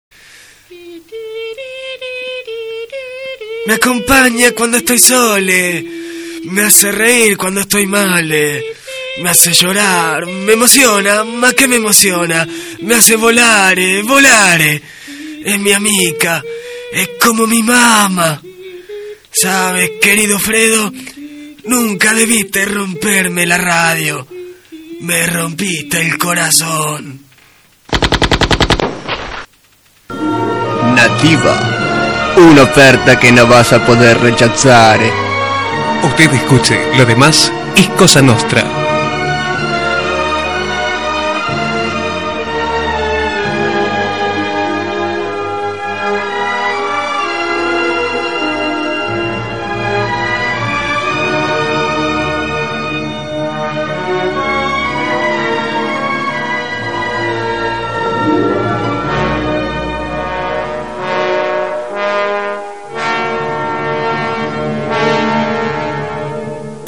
SPOT de EL PADRINO